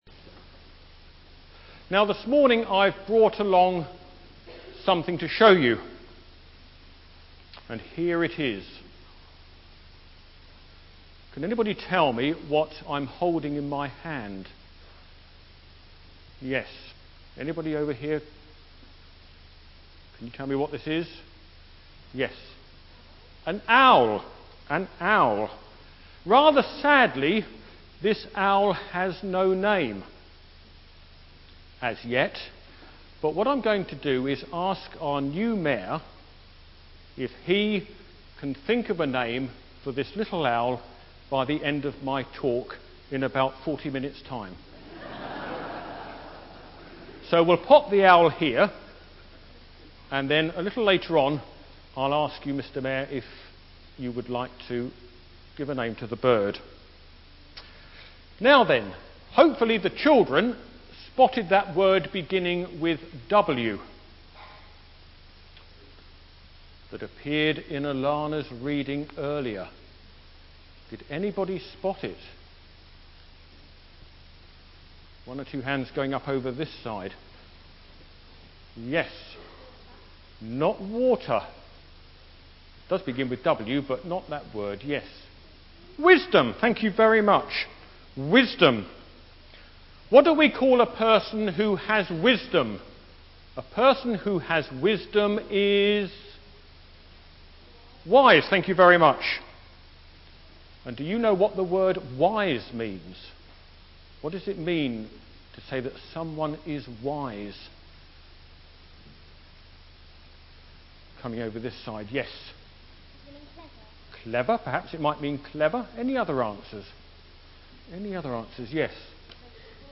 Civic Service at 11.30am